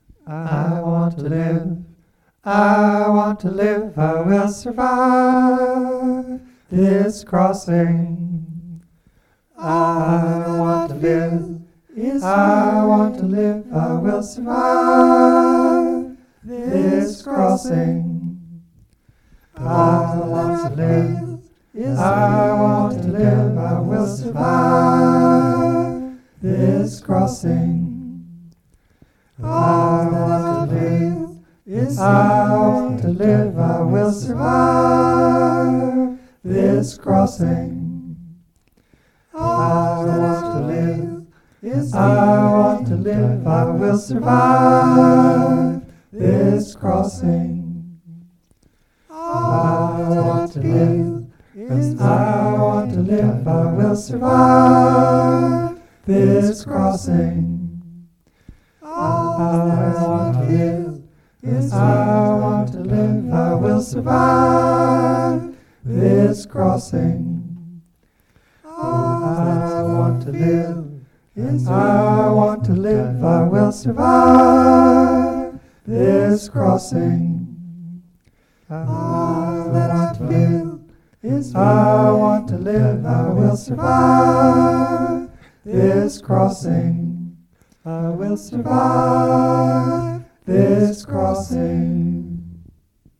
A three part a capella song: middle part: I want to survive, I want to survive, I will survive, this crossing. high part: All that I feel, is just feeling, I will survive, this crossing. low part: Those that survived, and those that died, help me survive, this crossing